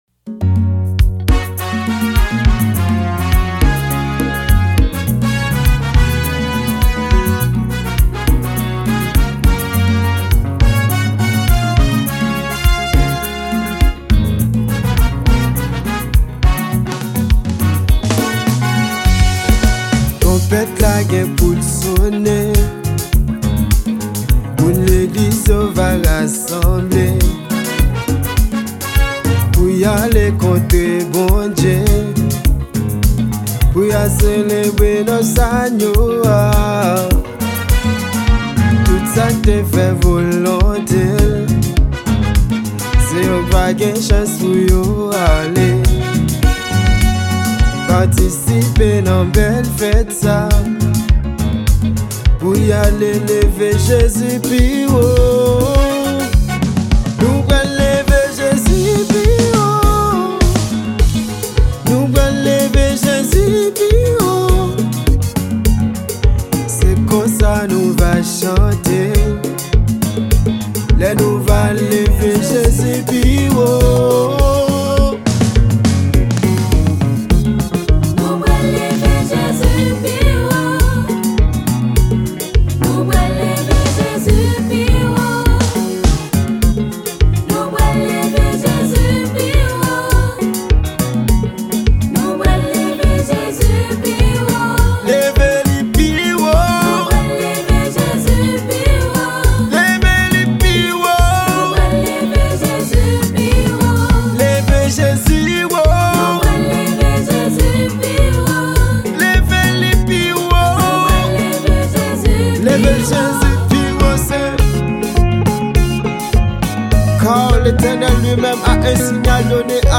Genre: GOSPEL.